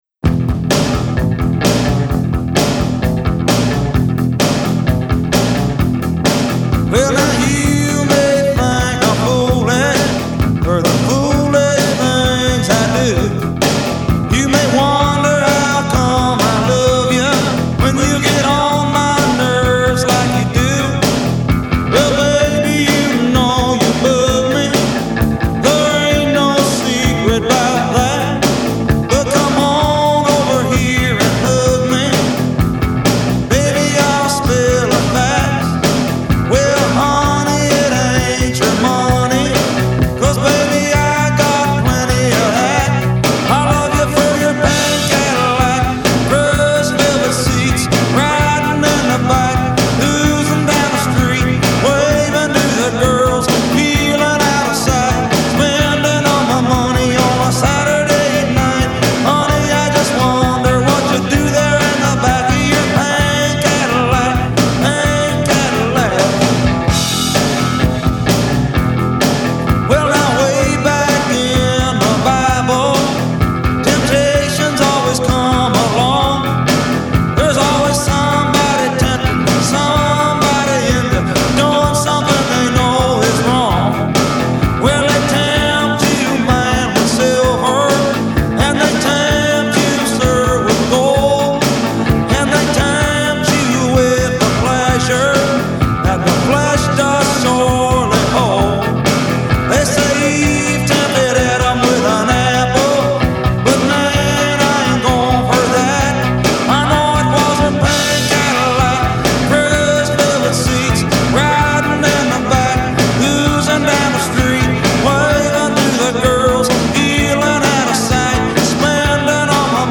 bluesy gruntalong